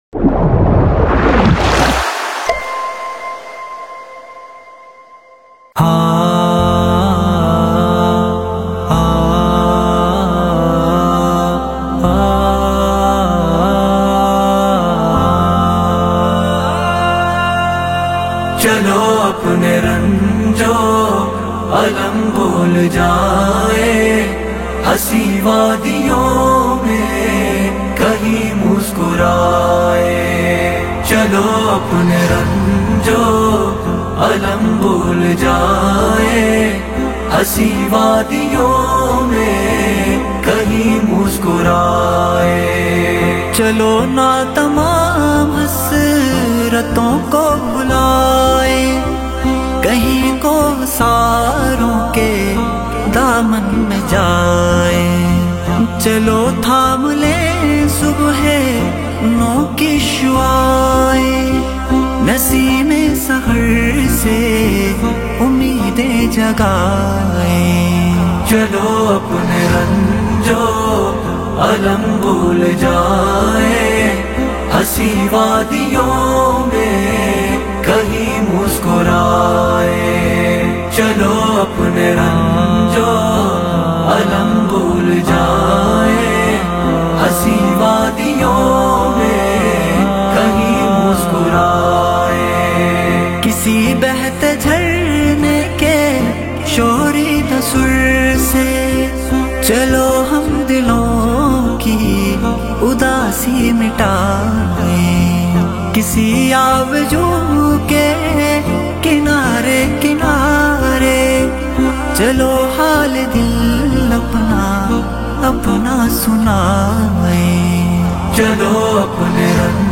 Beautiful Inspirational Nazam | Chalo Apne Ranj o Alam Bhol Jain | Urdu Ghazal